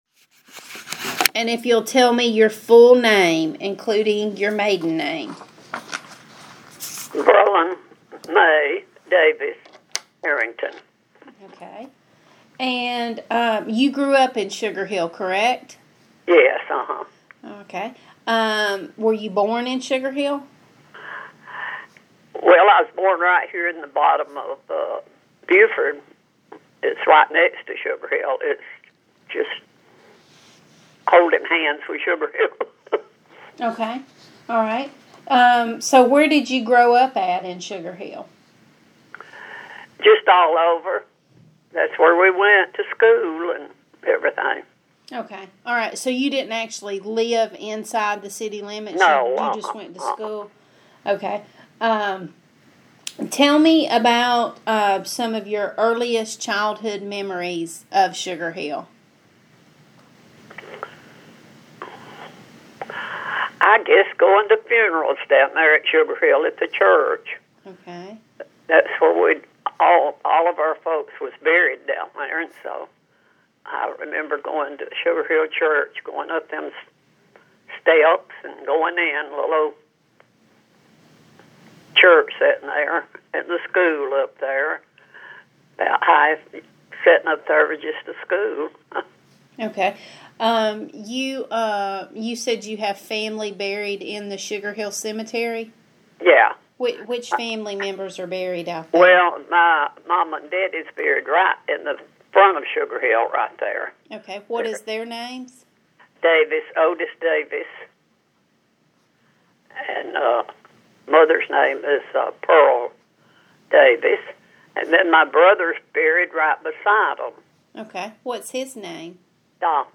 Audio Interview Subject Oral histories Sugar Hill
via telephone